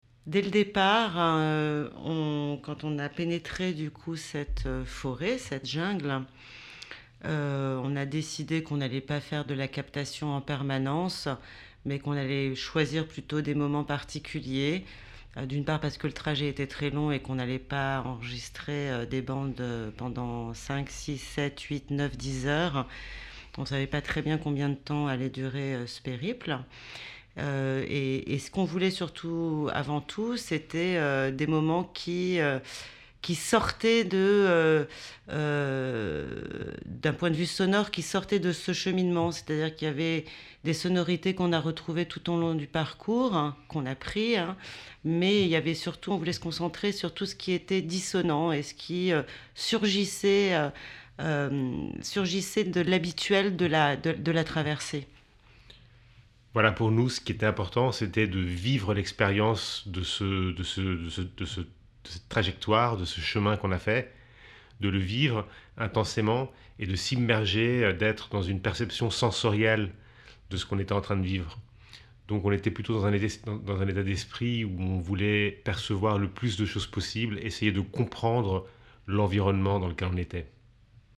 Extrait d’un entretien avec Nova Materia à propos du chef-d’œuvre „Xpujil“ (Made to Measure Vol. 45)
Interview-Deutsclandfunk_2.mp3